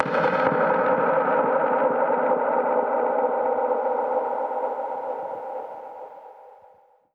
Index of /musicradar/dub-percussion-samples/134bpm
DPFX_PercHit_A_134-08.wav